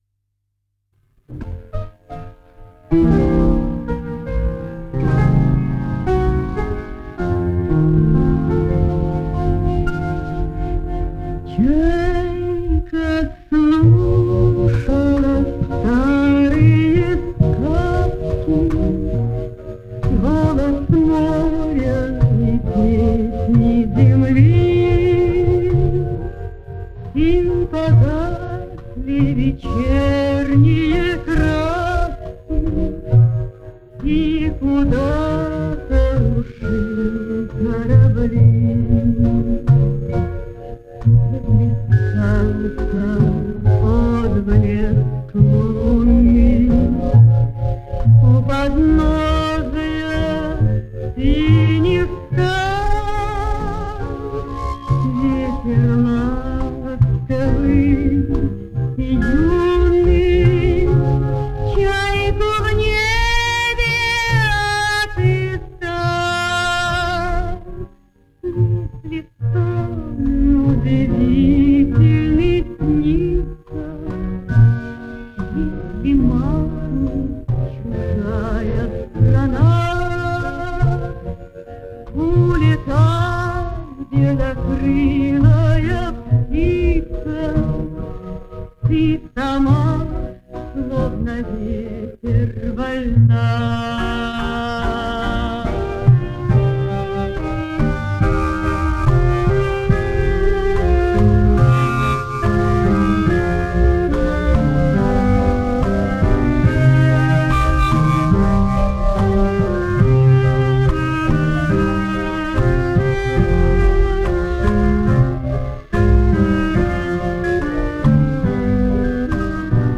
Это оригинал с пленки.